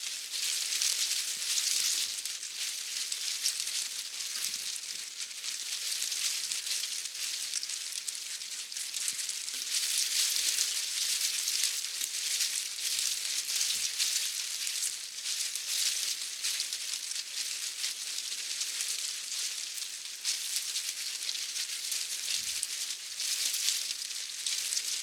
windbush_2.ogg